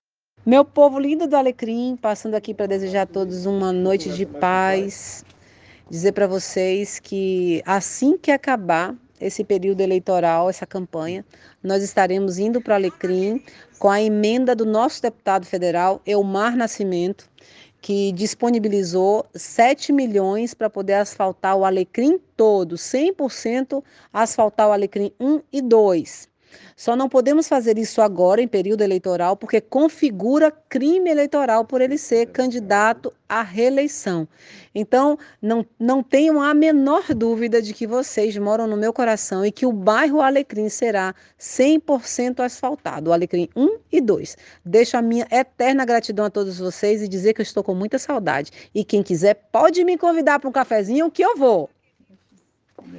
Mas a prefeita Cordélia Torres, além de gravar o vídeo, também gravou um áudio direcionado à população do bairro Alecrim, onde afirma que o deputado Elmar Nascimento, disponibilizou uma emenda de 7 milhões para asfaltar os bairros Alecrim I e II.